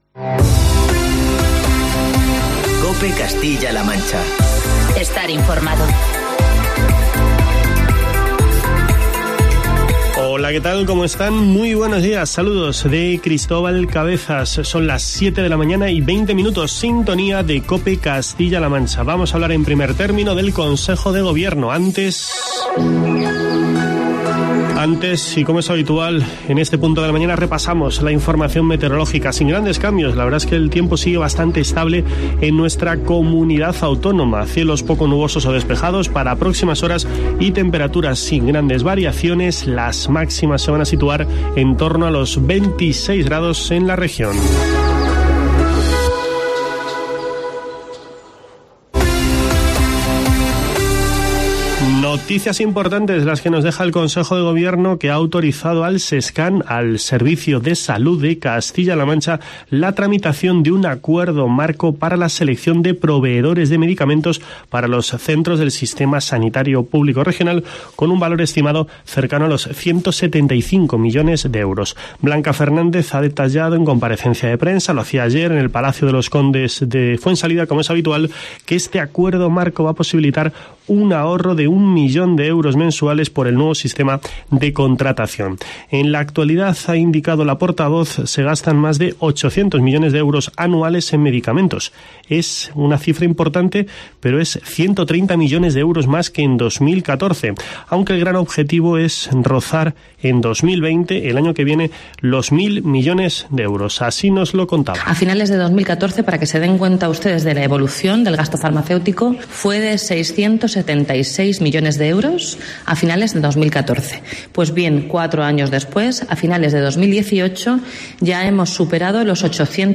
Es una de las noticias que te contamos en el informativo matinal de COPE Castilla-La Mancha